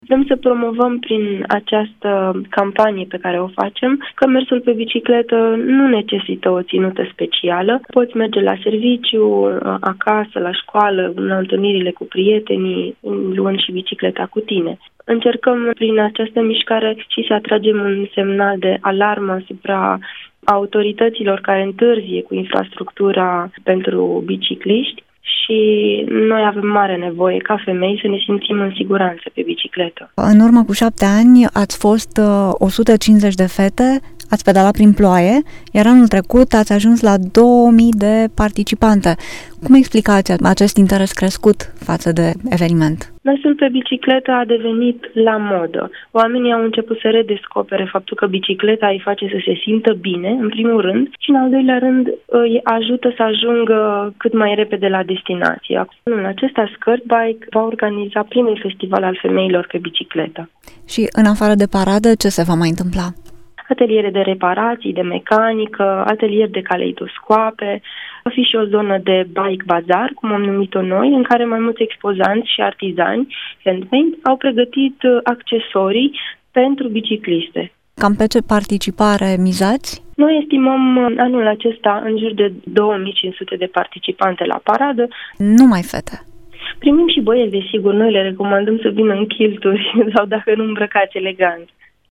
Emisiunea Drum cu Prioritate vă invită să le cunoaşteţi pe inițiatoarele acestei comunități a biciclistelor.